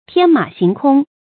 注音：ㄊㄧㄢ ㄇㄚˇ ㄒㄧㄥˊ ㄎㄨㄙ
天馬行空的讀法